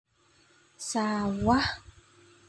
Sawah.mp3